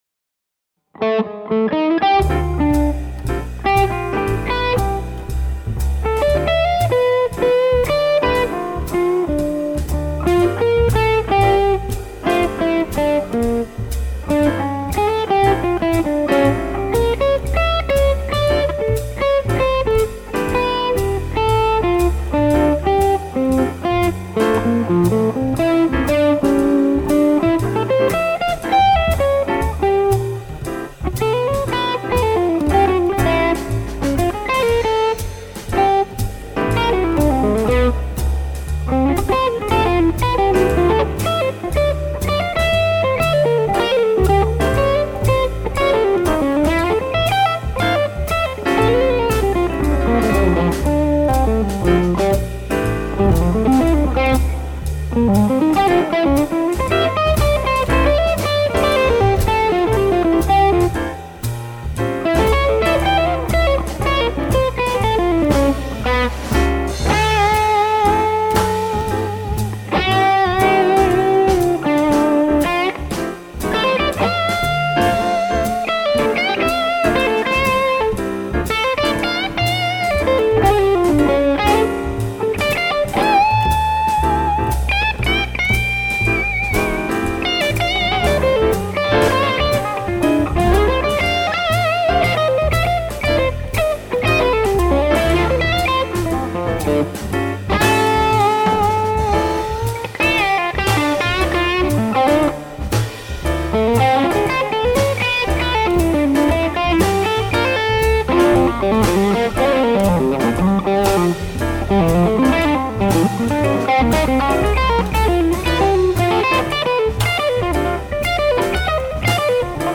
These clips are of my Fuchs ODS 100.
(od channel with new Weber Alnico 1265s)